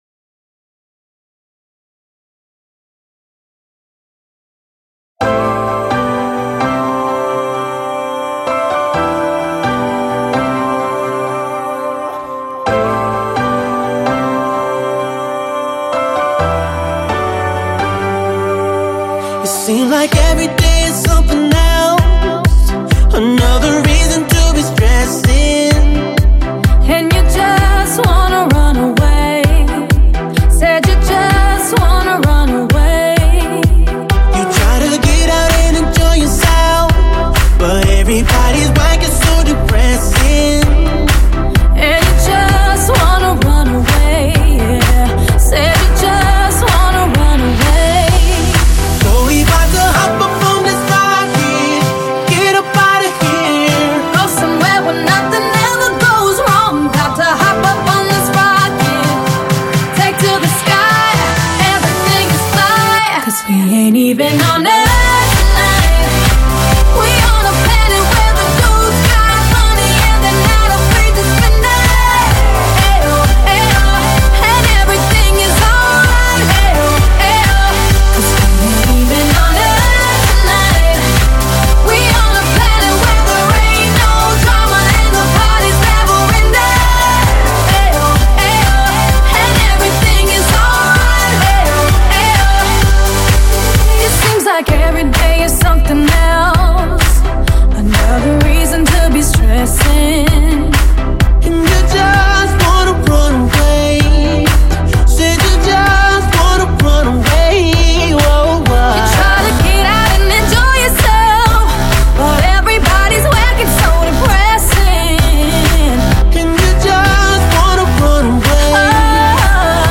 Electrodance